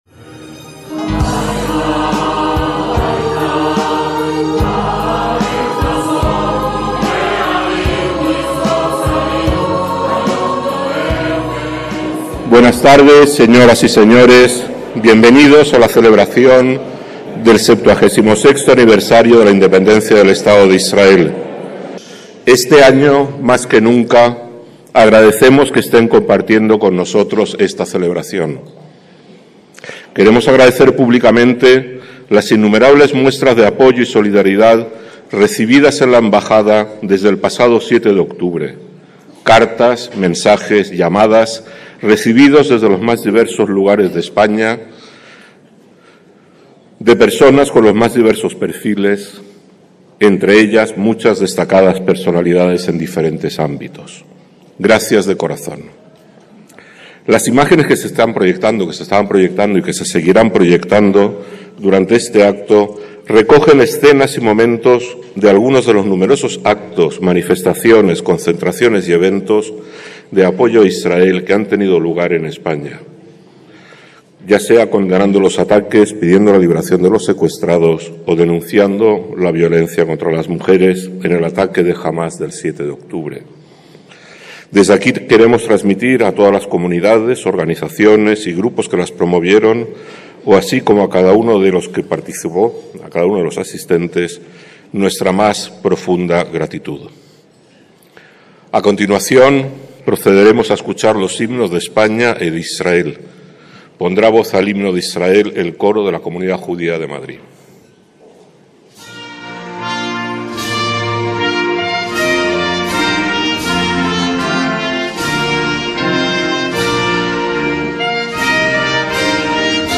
ACTO EN DIRECTO - El 13 de mayo de 2024 la Embajada de Israel en España celebró un acto en Madrid por los 76 años de independencia, al que asistieron personalidades de todos los ámbitos y que supone también la despedida durante el próximo año de la actual embajadora, Rodica Radian Gordon, quien pronunció un discurso agradeciendo las muestras de apoyo a su país en tiempos tan difíciles como los que está viviendo desde el pogromo del pasado 7 de octubre y la consecuente guerra.